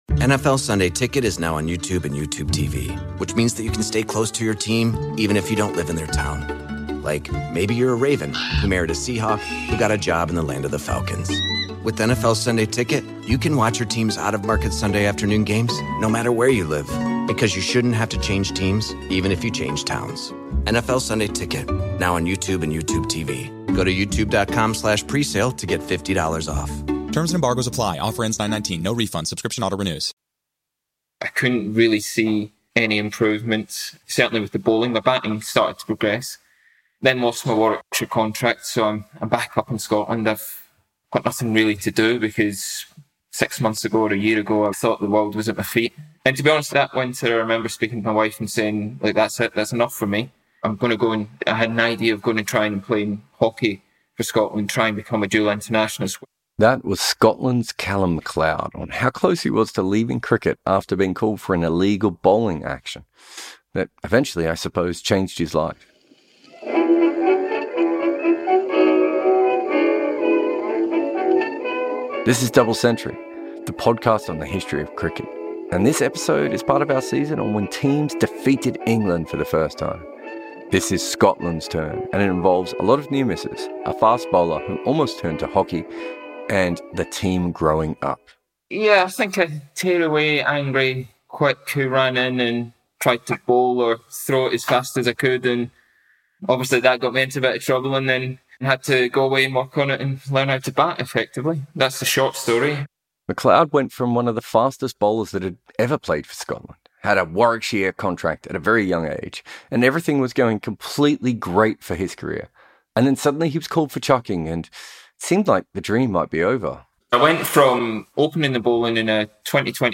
We talk to Calum MacLeod, who made a few that game, on what turned out to be an incredible day for the team that always seemed to miss its chance.